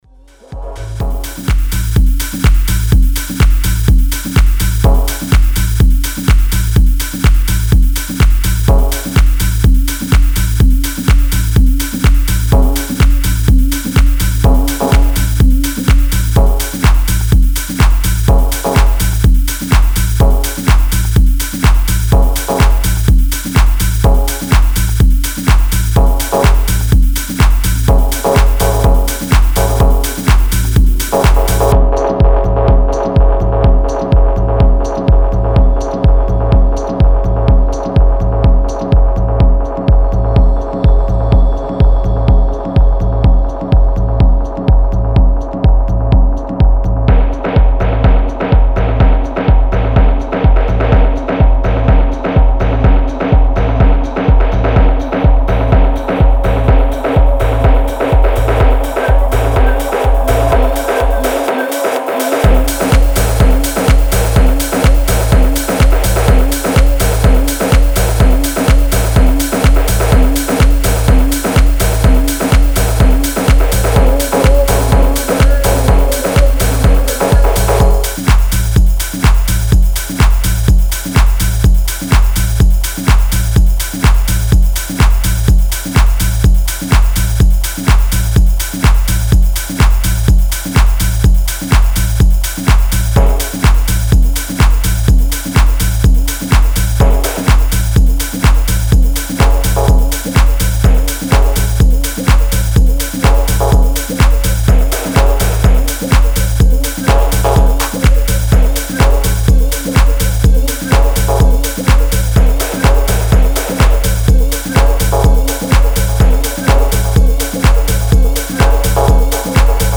Name is program: its Techno & its Raw.
Style: Tech House